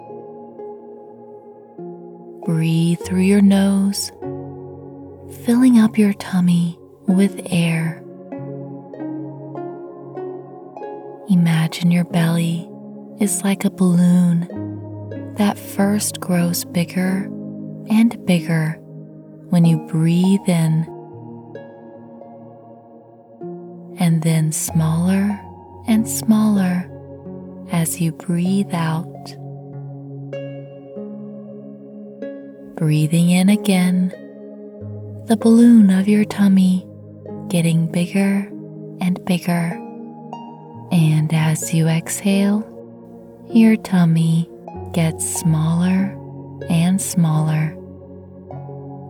Sleep for Kids is a simple relaxation hypnosis session, designed to help relax their body and calm their mind, through a simple story.